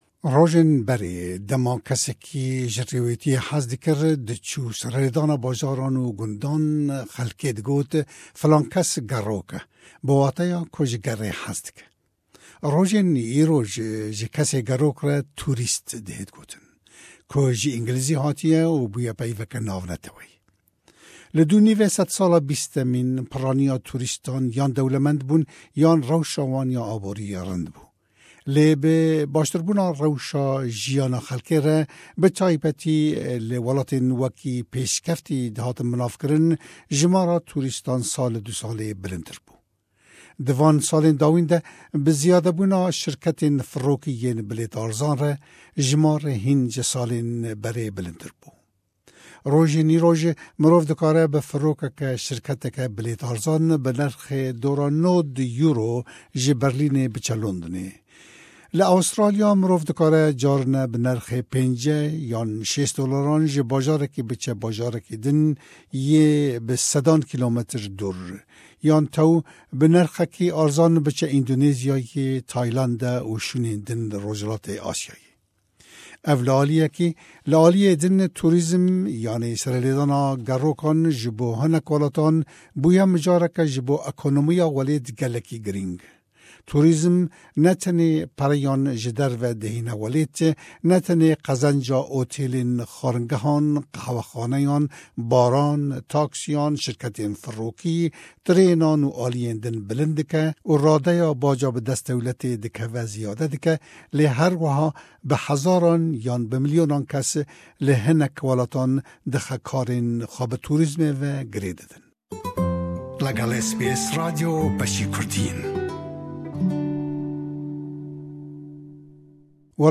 Bêtir ji vê raporta dengî.